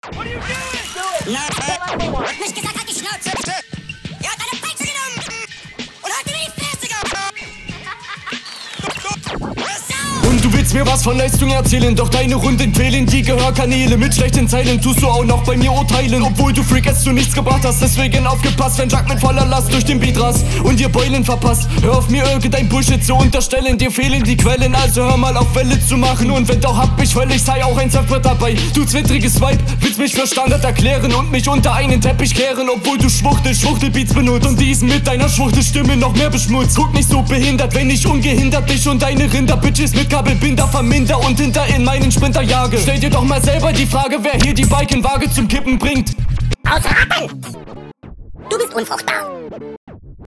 Ich habe hier das Gefühl du rennst dem Beat etwas hinterher.